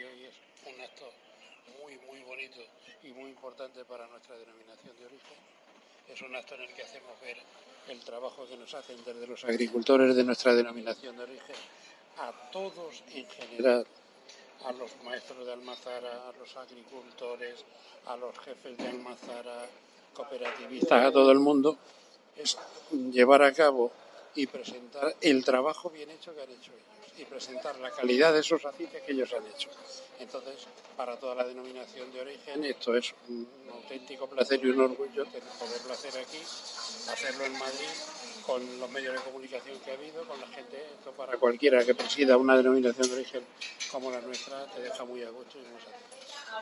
Corte voz